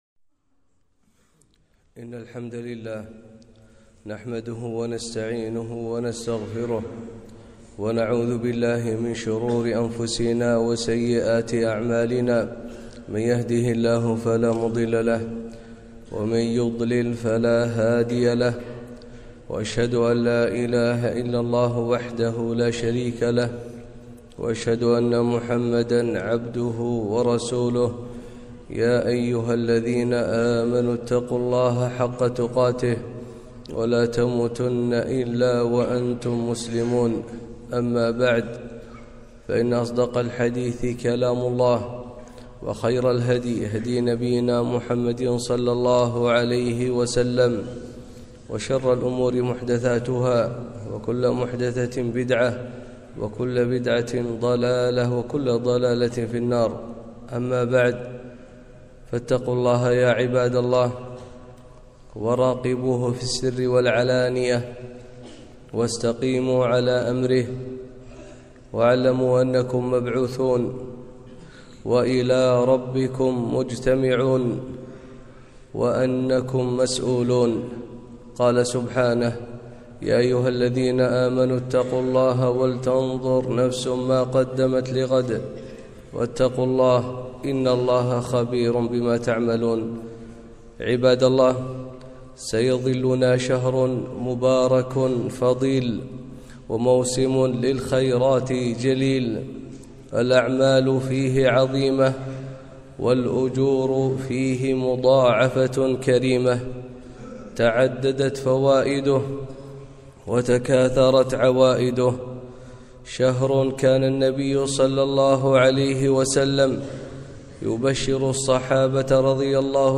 خطبة - أتاكم رمضان